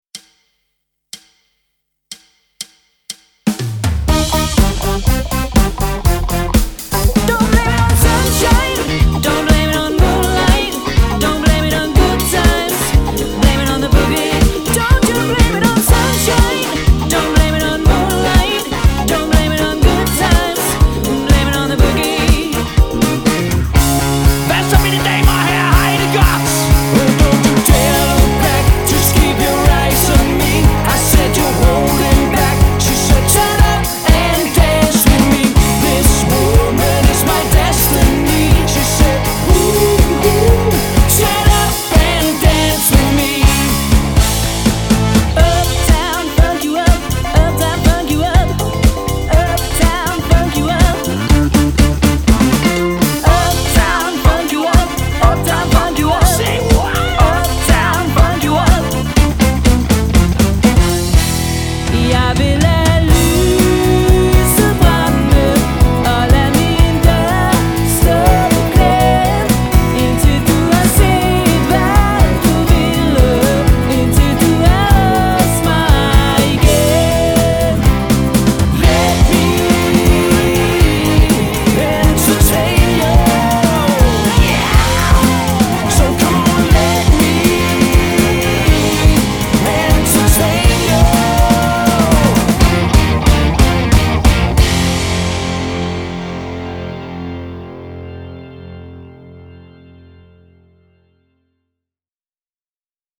• Allround Partyband
• Coverband